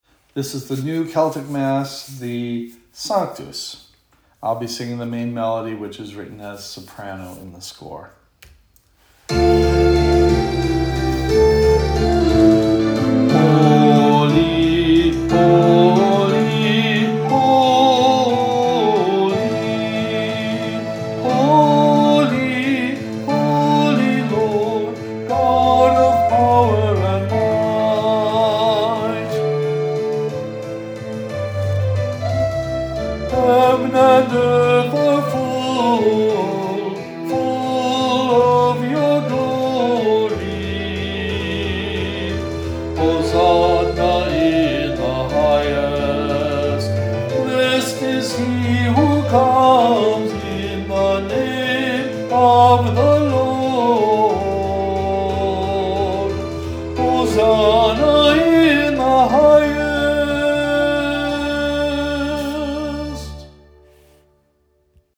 Sunday Worship 9am Music: Celtic Theme
At the 9am service of Holy Eucharist we are singing music from the New Celtic Mass by James Wallace.
New-Celtic-Mass-Sanctus-full-w-vocal.mp3